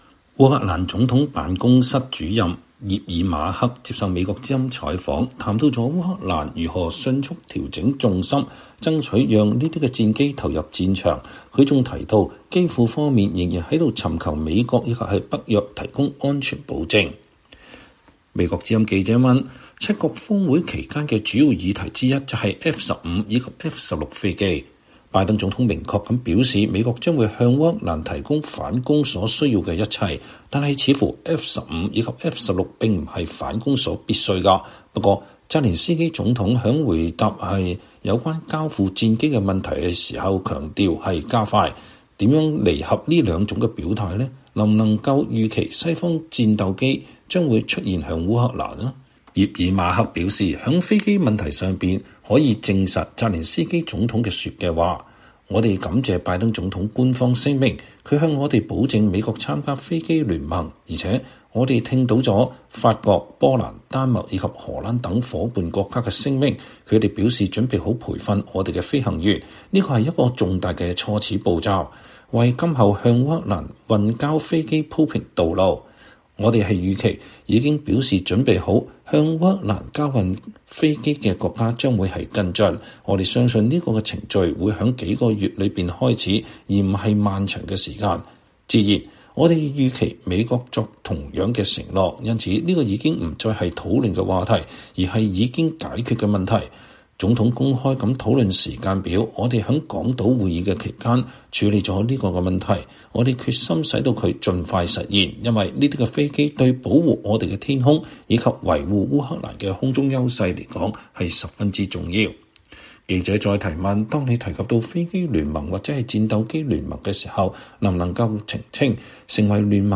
烏克蘭總統辦公室主任安德烈·葉爾馬克(Andrii Yermak)接受美國之音(VOA)採訪，談到烏克蘭如何迅速調整重心，爭取讓這些戰機投入戰場。